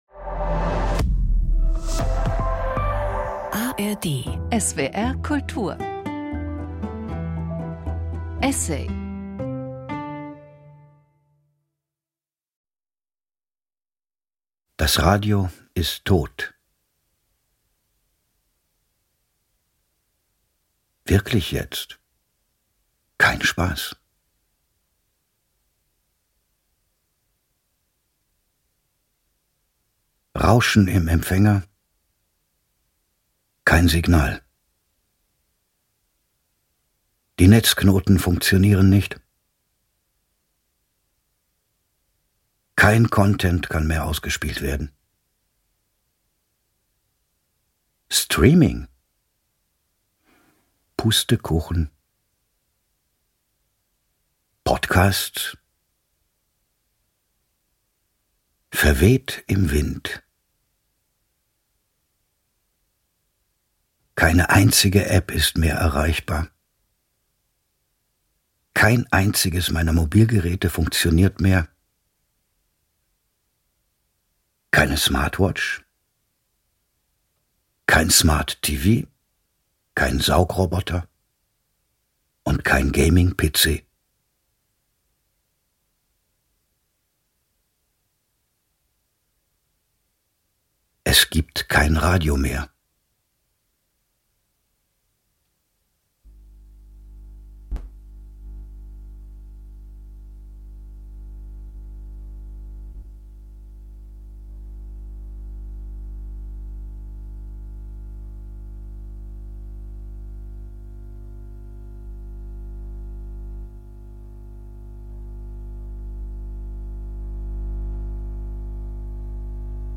Ein Essay, der von lauter Pausen zerfressen ist?